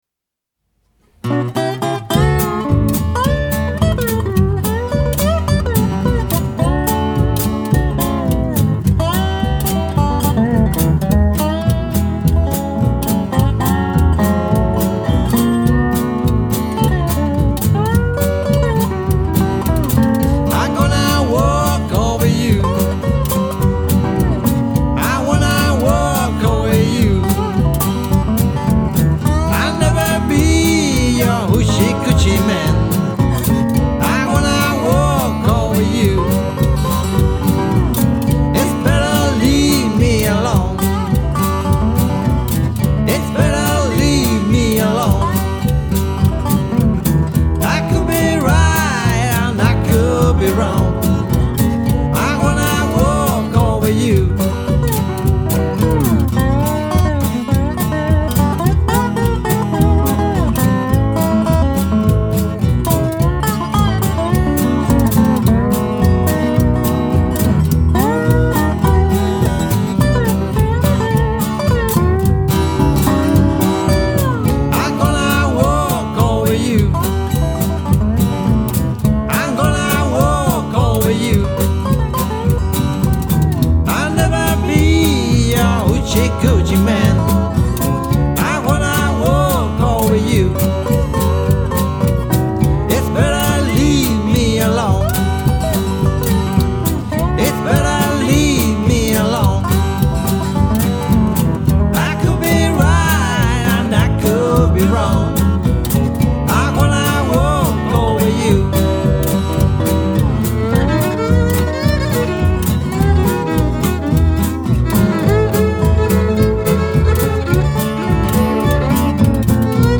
banjo, violin, mandolina, dobro, contrabajo, percusión